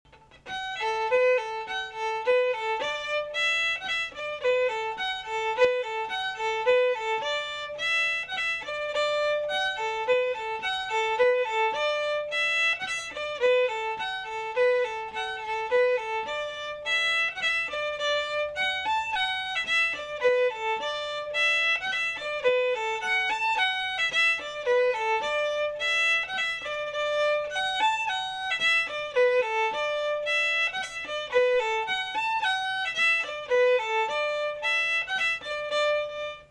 Session Tunes
eganspolka.mp3